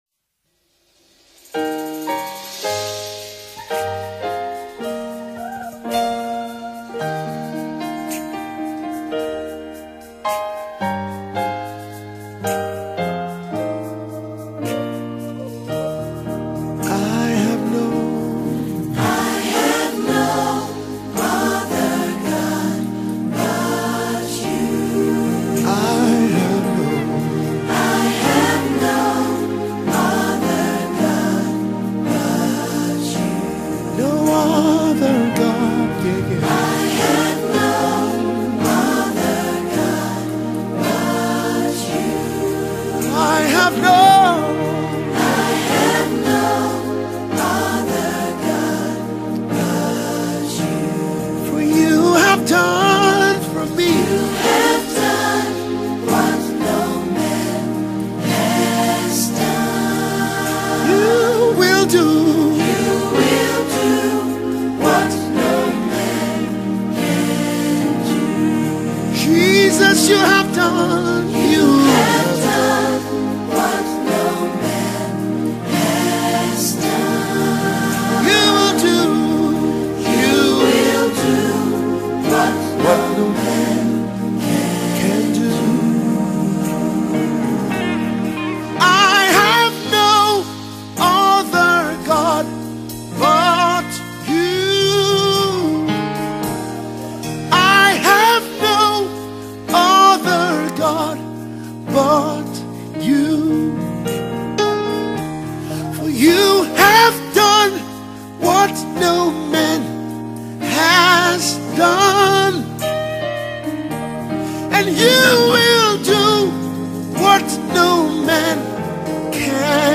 this is a very powerful gospel music.